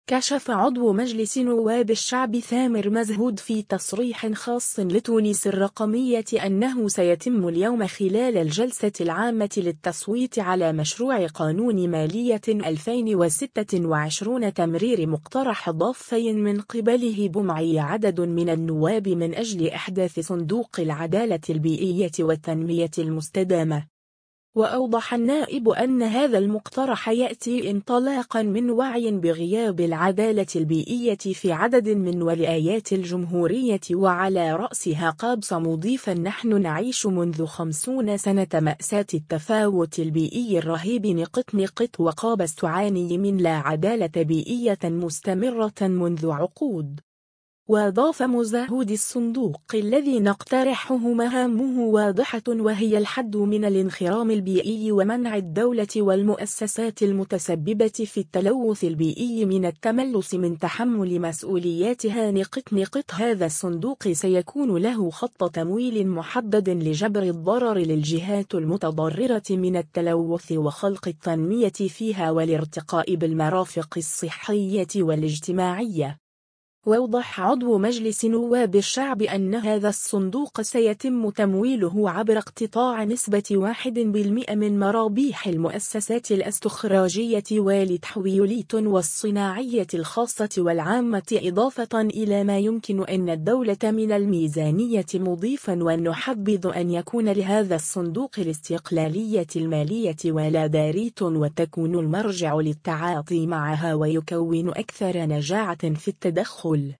كشف عضو مجلس نواب الشعب ثامر مزهود في تصريح خاص لـ”تونس الرقمية” أنه سيتم اليوم خلال الجلسة العامة للتصويت على مشروع قانون مالية 2026 تمرير مقترح اضافي من قبله بمعية عدد من النواب من أجل إحداث صندوق العدالة البيئية والتنمية المستدامة.